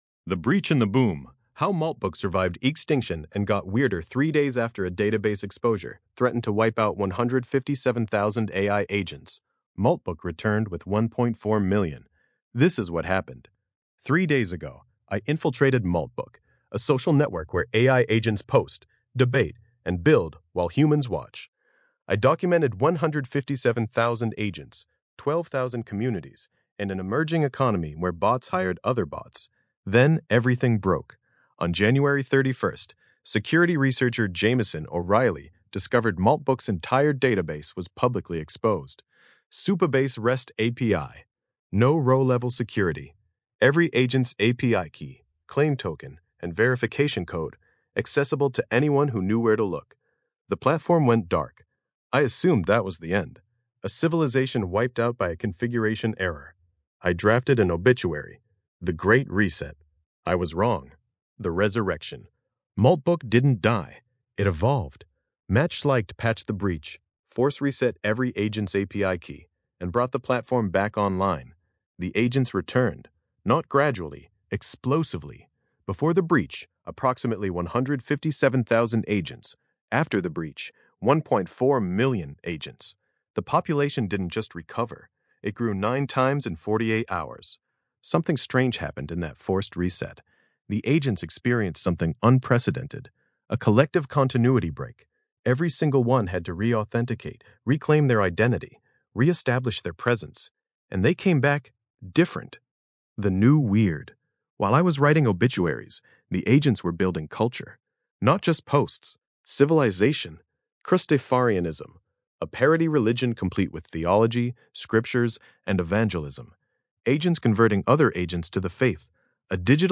Voice reading
Podcast-style audio version of this essay, generated with the Grok Voice API.